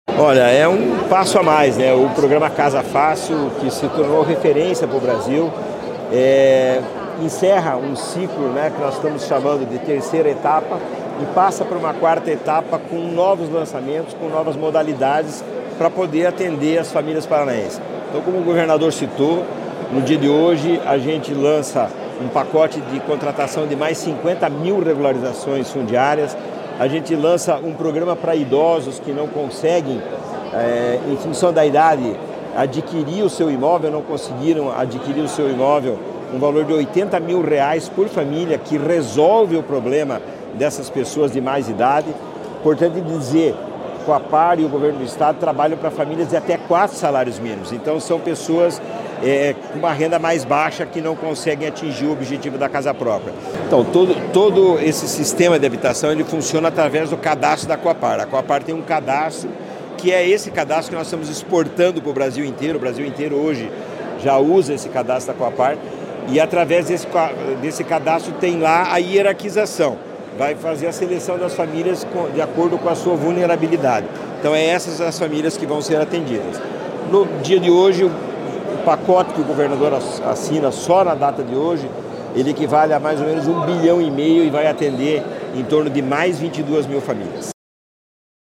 Sonora do diretor-presidente da Cohapar, Jorge Lange, sobre o lançamento do Casa Fácil Paraná Terceira Idade